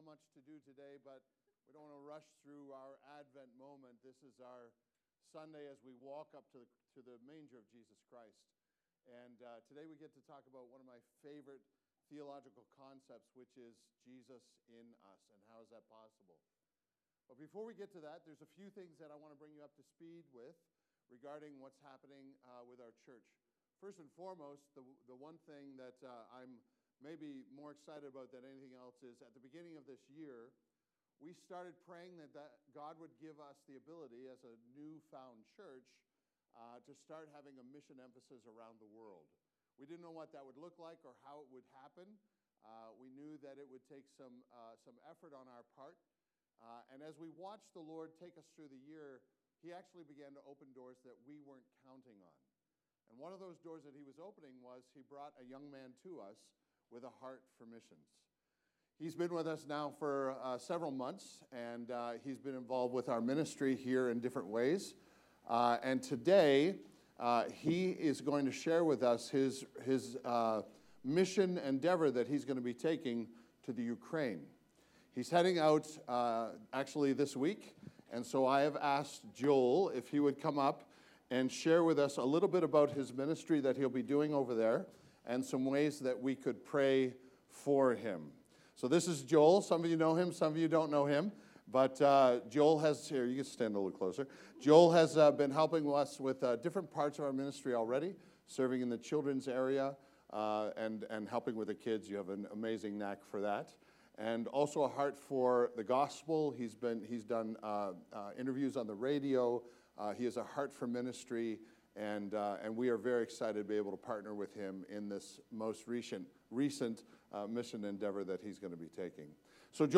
Village Church East: Sermons